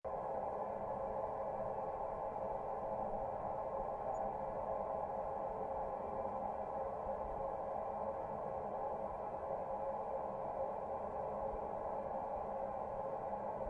cave_wind.wav